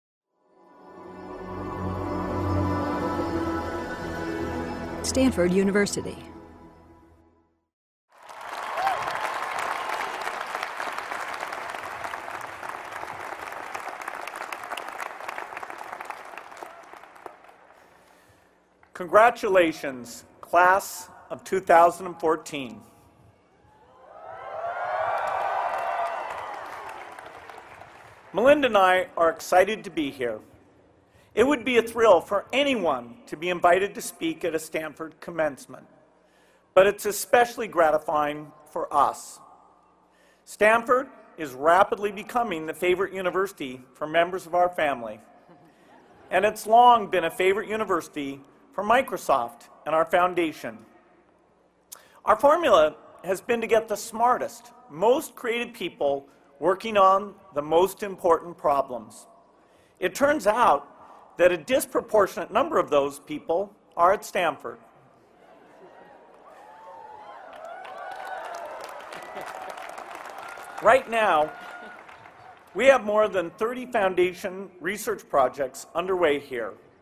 公众人物毕业演讲第20期:比尔盖茨夫妇于斯坦福大学(1) 听力文件下载—在线英语听力室